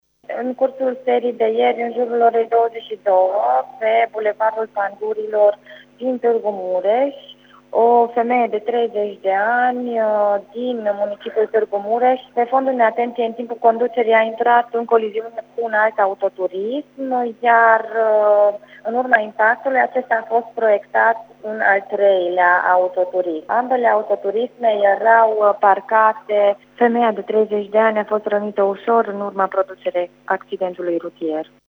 Cu detalii